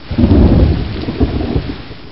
Thunder And Rain